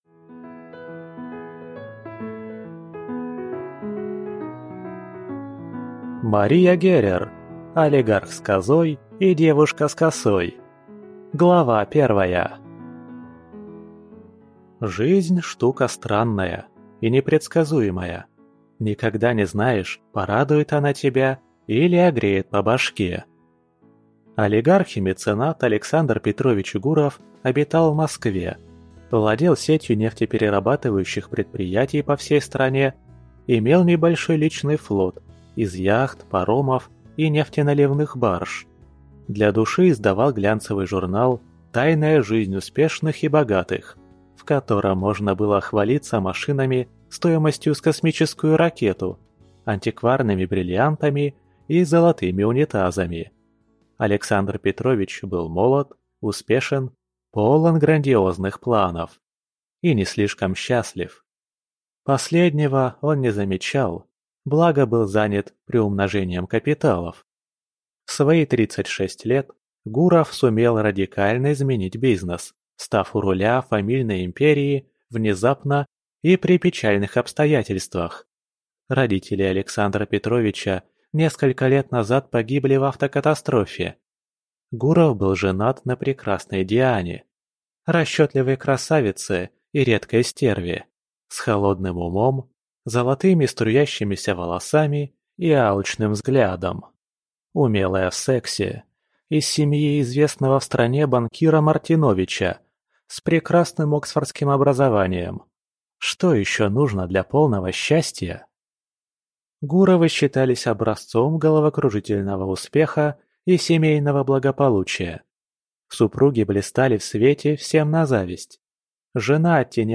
Аудиокнига Олигарх с козой и девушка с косой | Библиотека аудиокниг